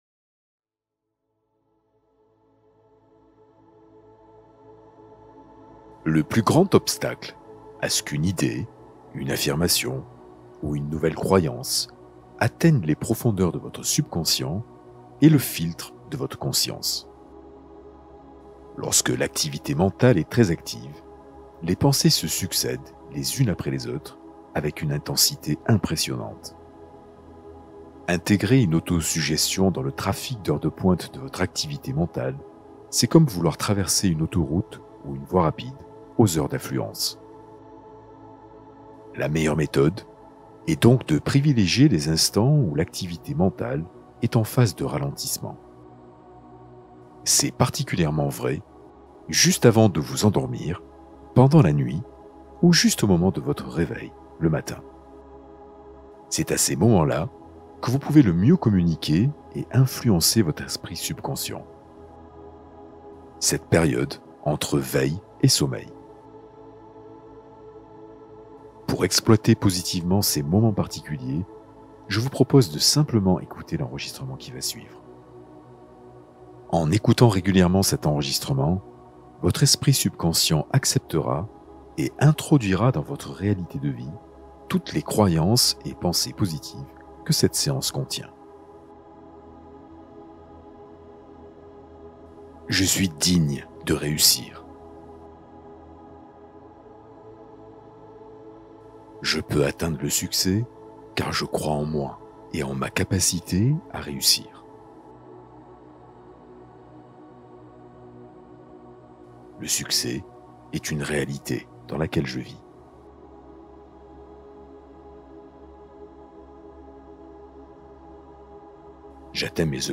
Méditation guidée pour transformer ton énergie et changer ta vie